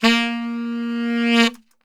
A#1 SAXSWL.wav